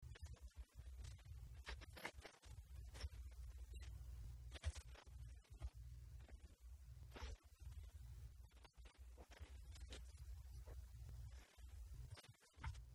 Township Council convened in Special Session, and first heard representations from members of the public in attendance at the meeting as to their preferences